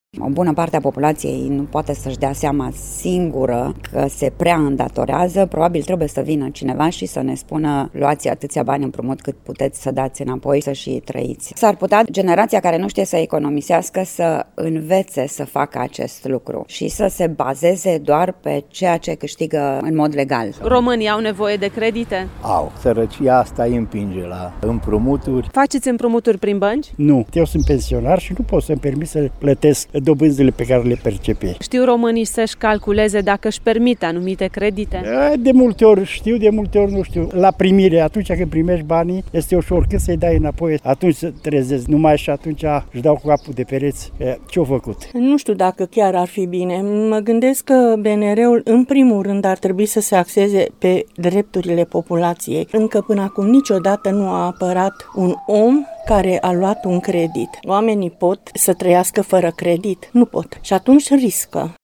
Mureșenii au păreri împărțite când vine vorba de o asemenea plafonare, însă majoritatea cred că în România e greu să trăiești fără credite bancare: